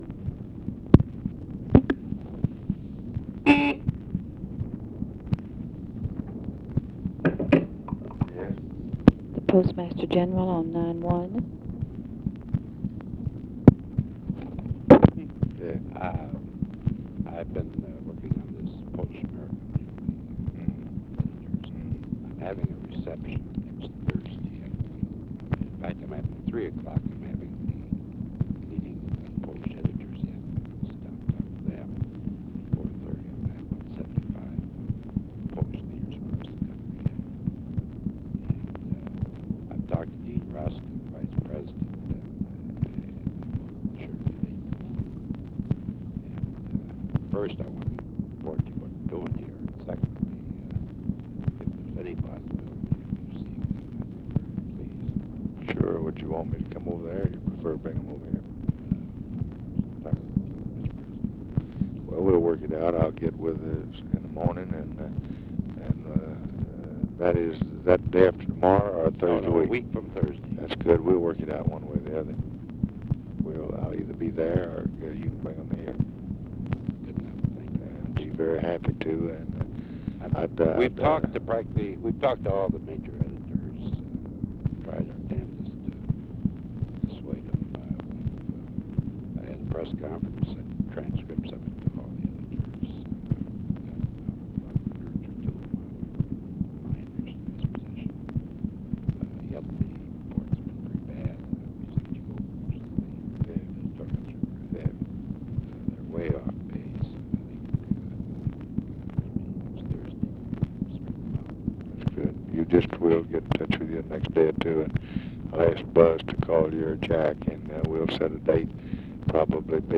Conversation with JOHN GRONOUSKI, September 15, 1965
Secret White House Tapes